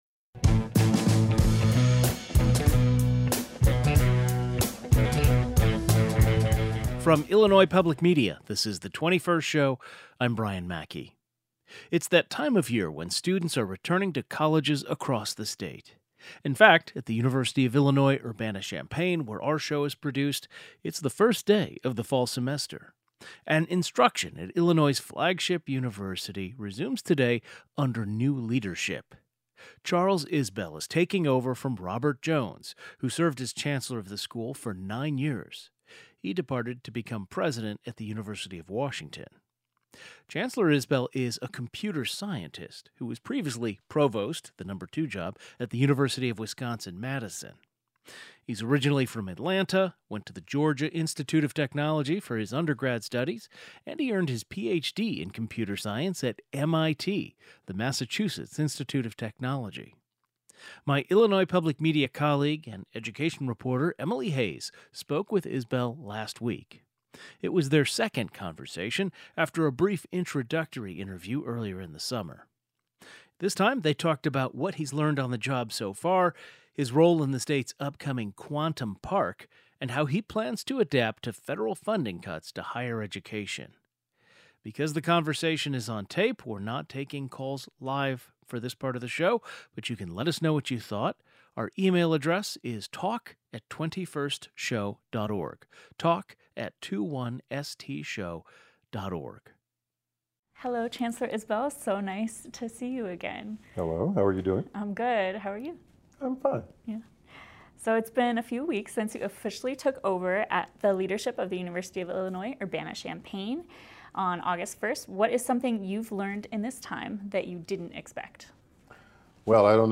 They discussed what he's learned on the job so far, his role in the state’s upcoming quantum park, and how he plans to adapt to federal funding cuts to higher education. The 21st Show is Illinois' statewide weekday public radio talk show, connecting Illinois and bringing you the news, culture, and stories that matter to the 21st state.